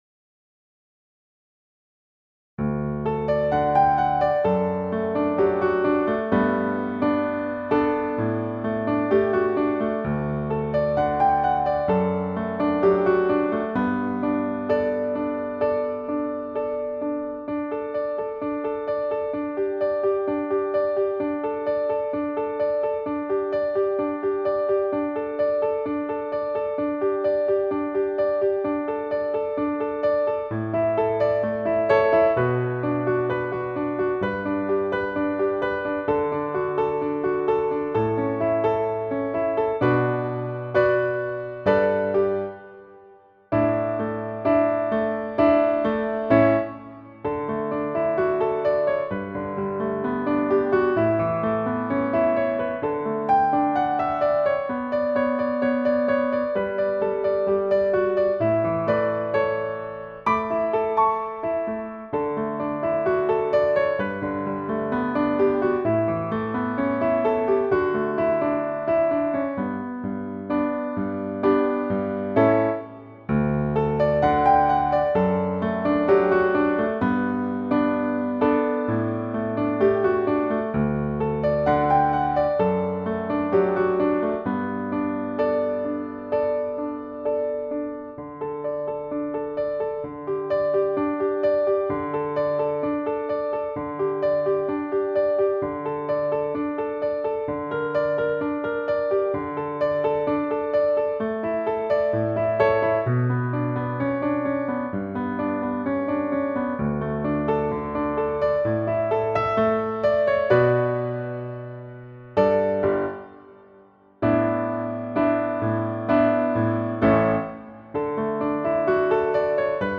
NewLifebackingtracksoundfilemp3.mp3